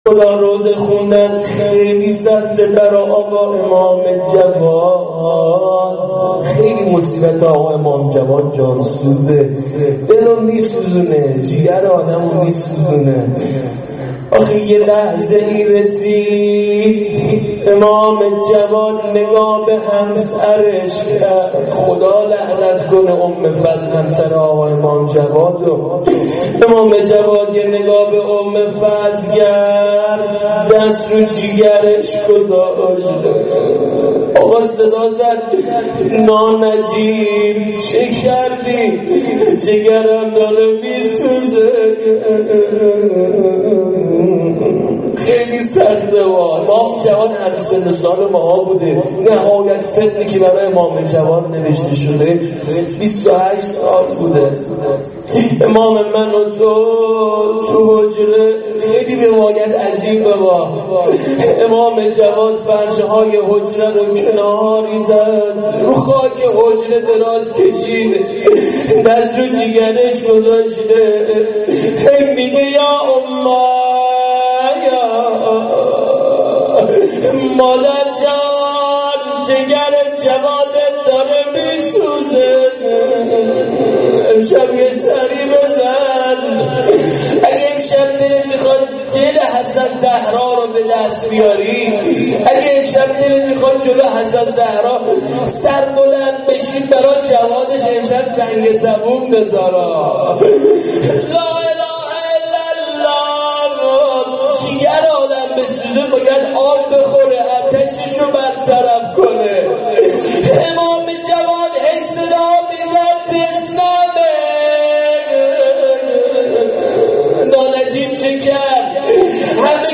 روضه حضرت جوادالائمه بعد از دعای توسل.mp3
روضه-حضرت-جوادالائمه-بعد-از-دعای-توسل.mp3